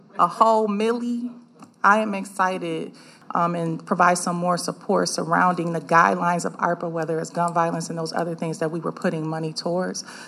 Commissioner Monteze Morales thinks it should be spent in the community.